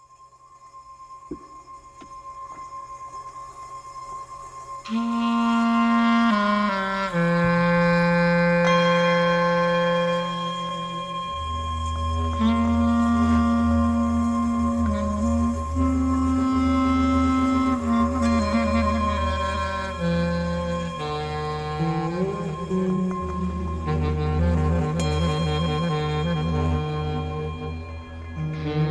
Category: Islamic Ringtones